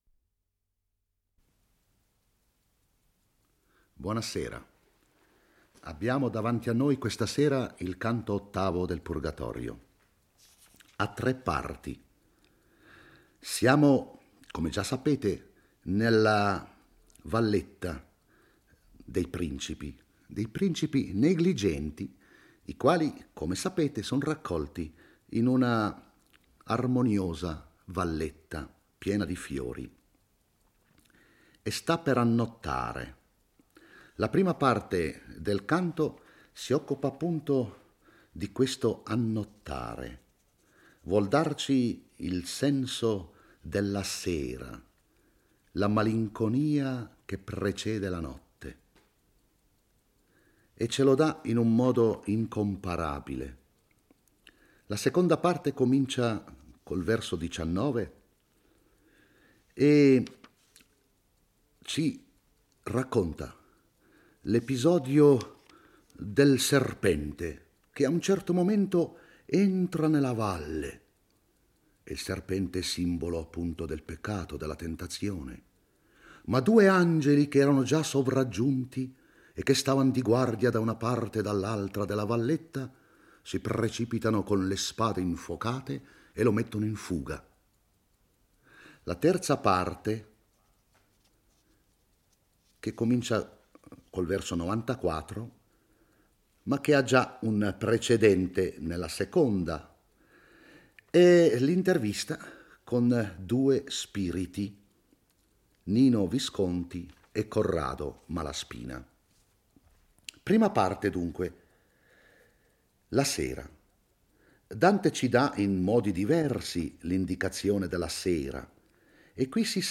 legge e commenta il canto VIII del Purgatorio. Sta calando la sera e Dante e Virgilio, in compagnia di Sordello, aspettano di proseguire il cammino verso il Purgatorio. Nell'attesa scendono nella valle in cui abitano le anime dei principi negligenti.